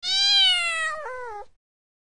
Cat Meow 12 Fx Bouton sonore